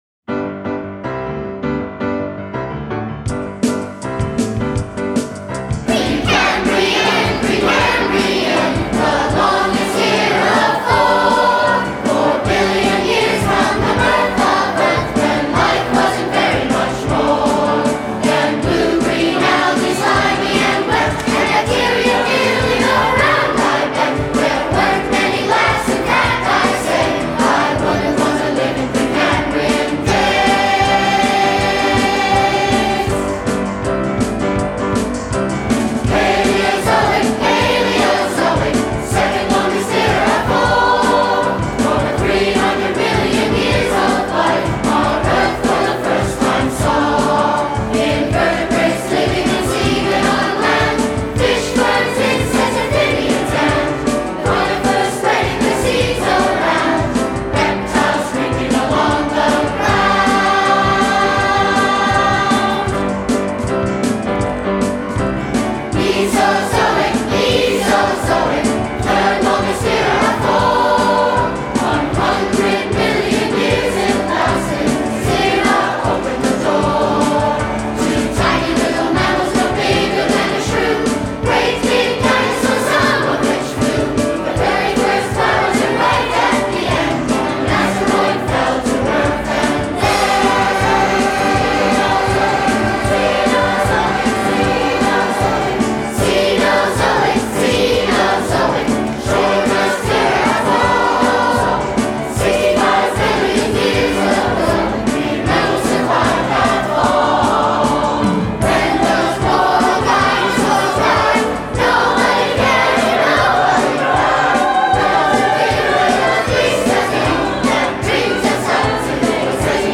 Below you can hear the 2007 Festival Chorus performing Lifetime: Songs of Life and Evolution.